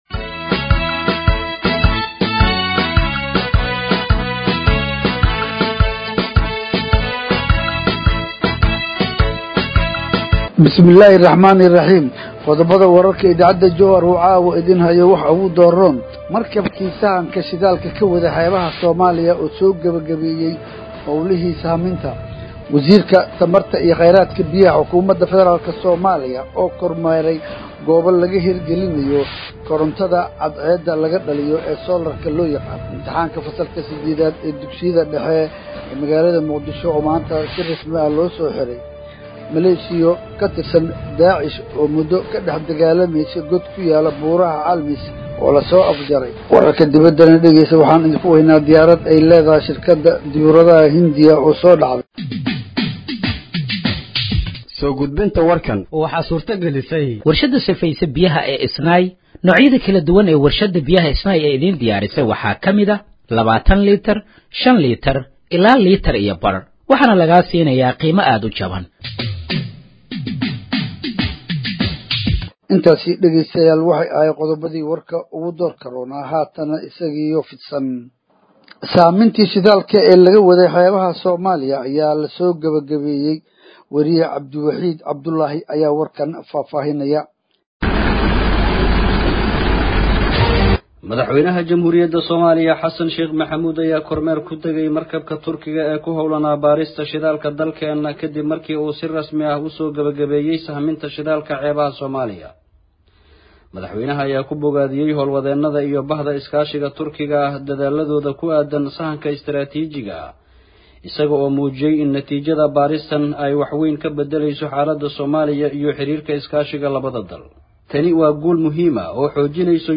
Dhageeyso Warka Habeenimo ee Radiojowhar 12/06/2025